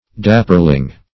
Dapperling \Dap"per*ling\, n. A dwarf; a dandiprat.